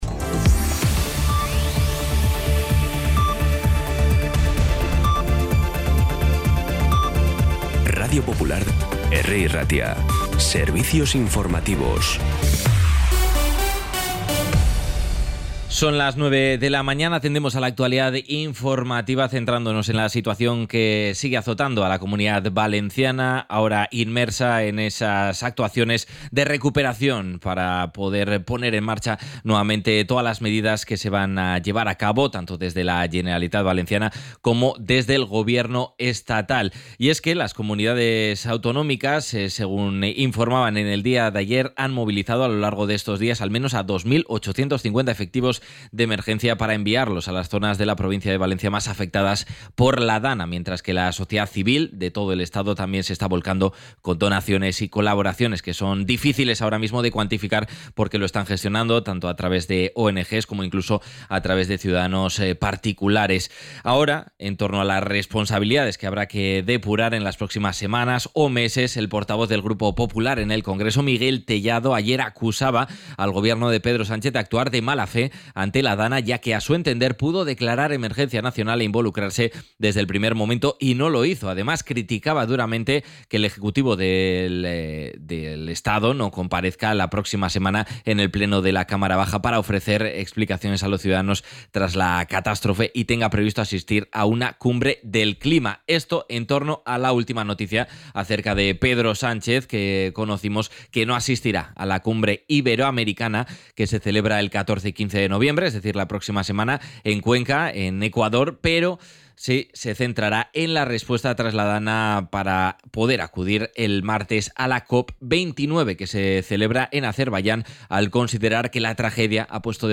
Las noticias de Bilbao y Bizkaia del 8 de noviembre a las 9